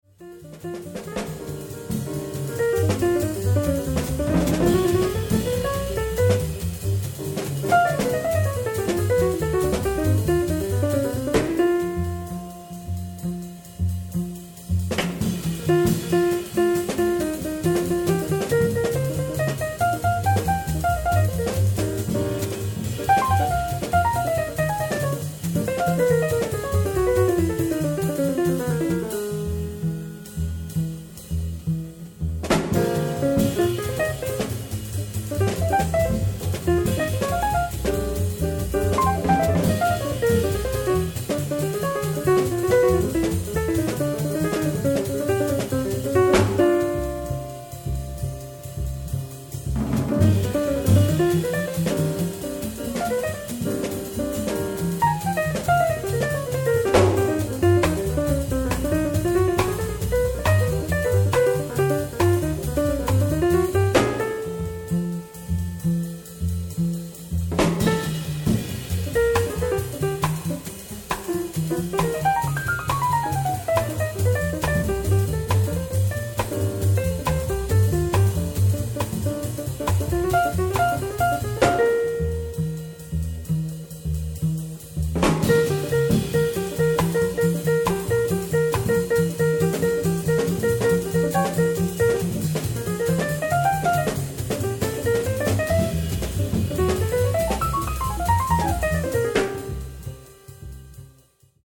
almost completely improvised over Latin percussion
The setup is quite spare
Soul Funk